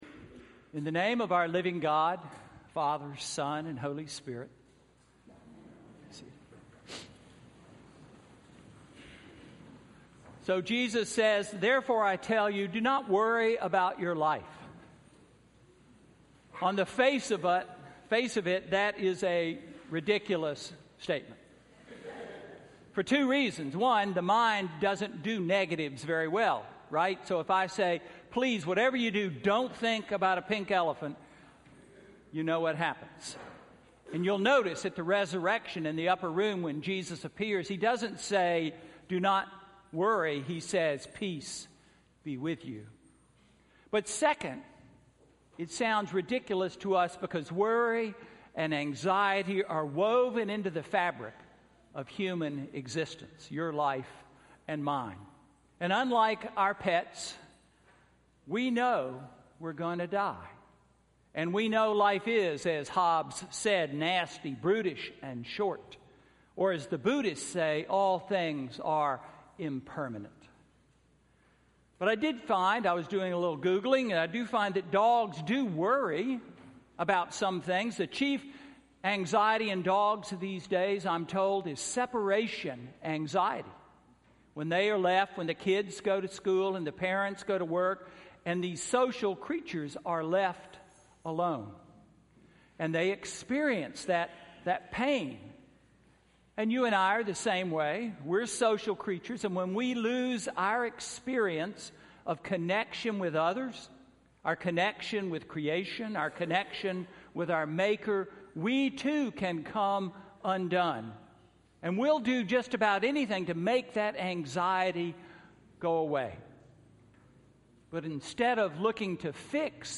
Sermon–October 4, 2015